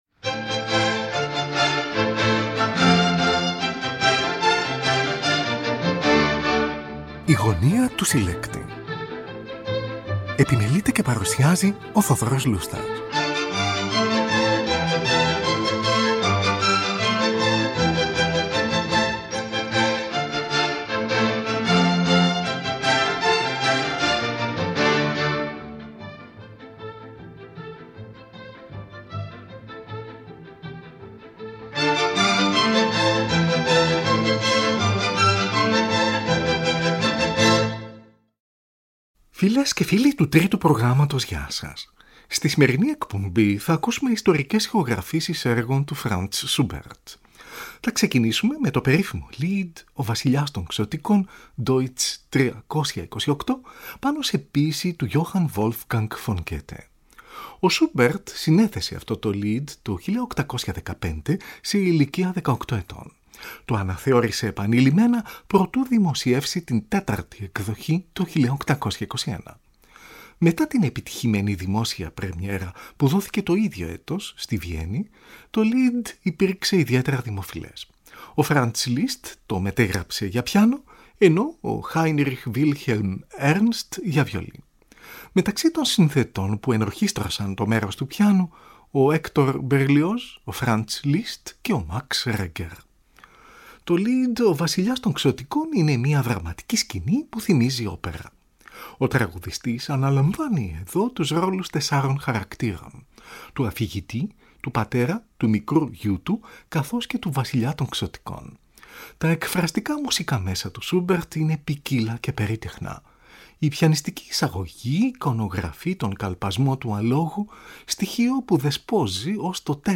Tη Φιλαρμονική Ορχήστρα της Βιέννης διευθύνει ο Wilhelm Furtwängler , από ραδιοφωνική ηχογράφηση στις 2 ή 3 Ιουνίου 1944 . Συμφωνία Αρ. 3, D.200. Την Ορχήστρα RIAS του Βερολίνου διευθύνει ο Igor Markevitch , από ζωντανή ηχογράφηση στις 2 Μαρτίου 1953 .
Τον βαθύφωνο Herbert Alsen συνοδεύει ο πιανίστας Michael Raucheisen , από ραδιοφωνική ηχογράφηση άγνωστης χρονολογίας, κατά τη διάρκεια του Δευτέρου Παγκοσμίου Πολέμου .